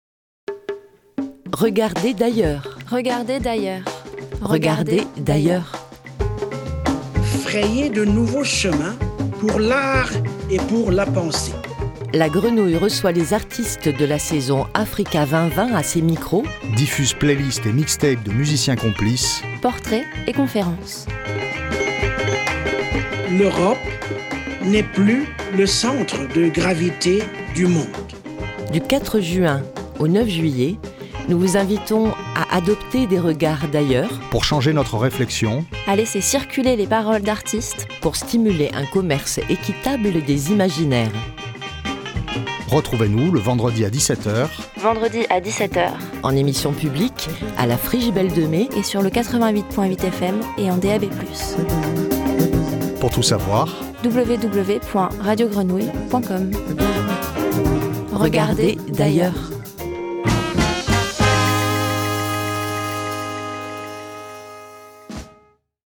promo-africa-2020.mp3